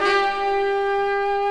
G_FunkTrumpetChord01.wav